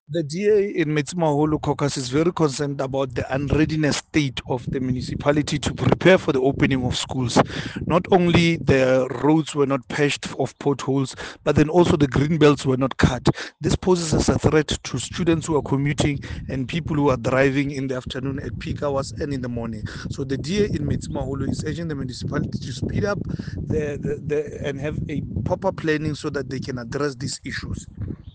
Sesotho soundbites by Cllr Thabang Rankoe